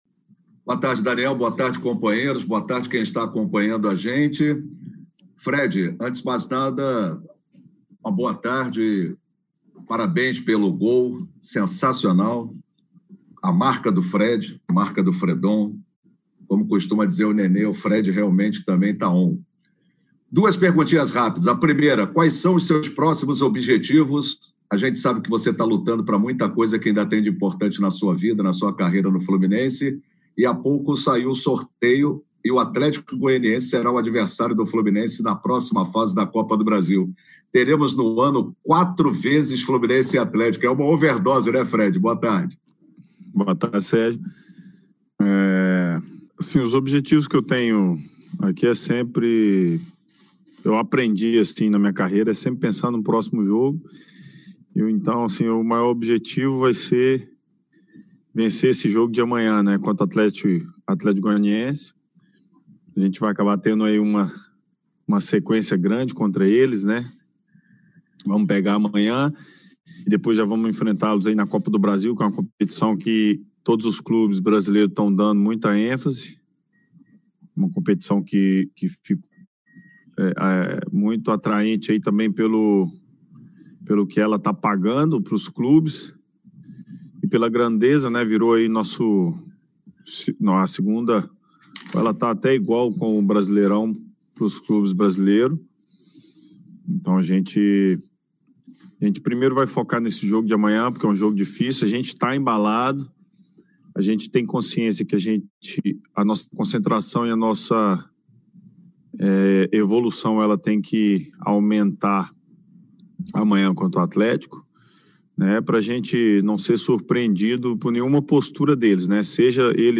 O atacante Fred participou da videoconferência desta terça-feira e demostrou muita alegria e motivação por ter marcado o seu primeiro gol no clássico contra o Vasco na sua volta ao Fluminense.